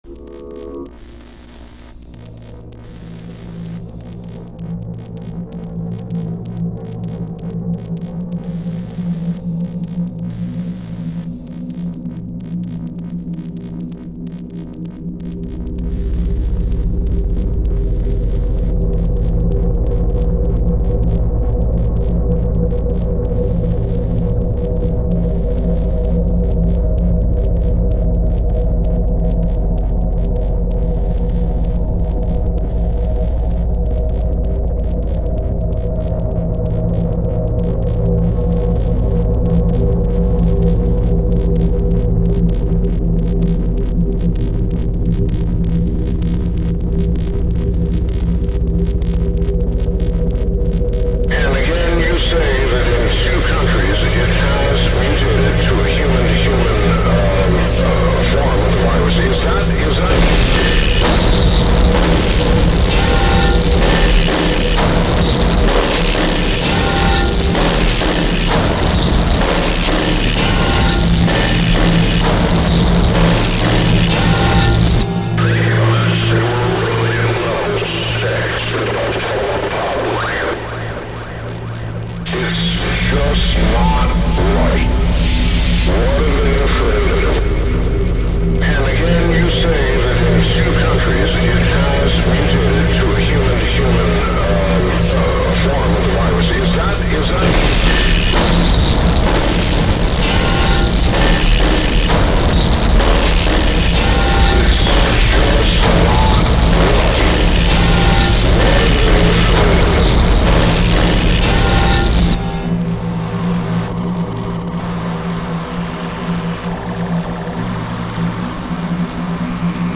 File under Unrelenting neural assault